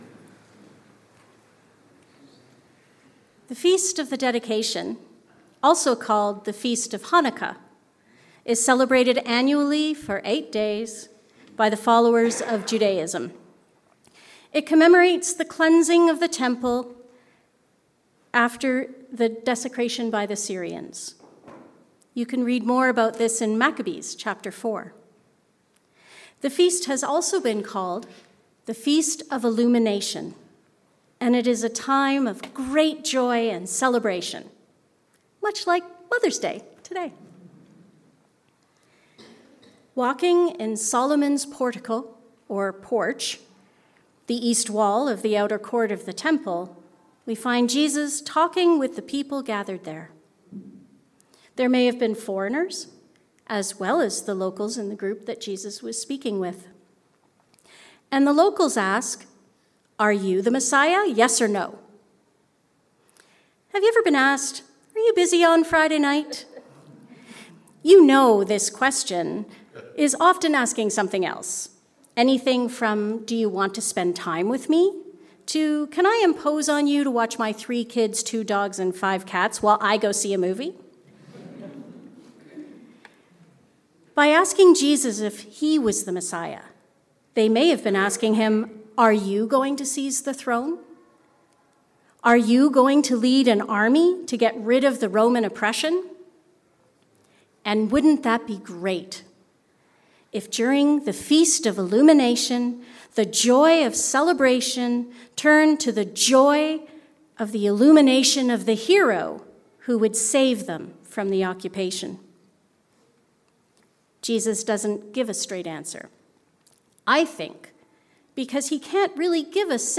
Belonging to Jesus. A sermon for the 4th Sunday of Easter